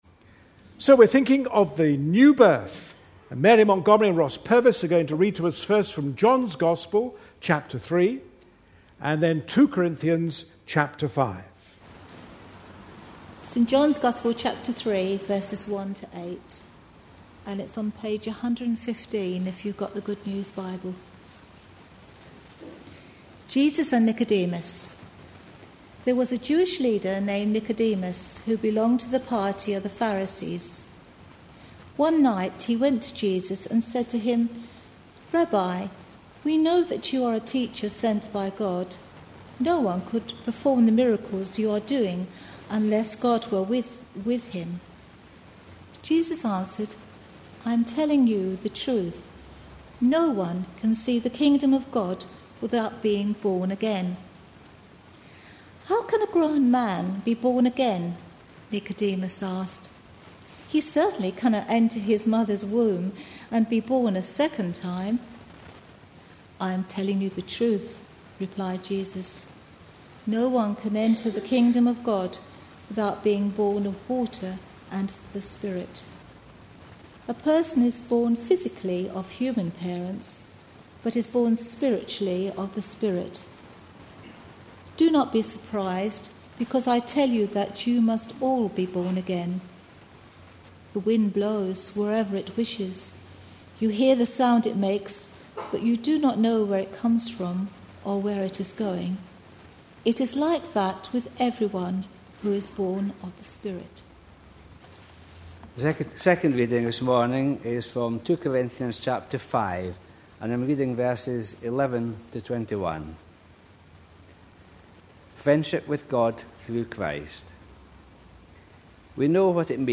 A sermon preached on 26th June, 2011, as part of our God At Work In Our Lives. series.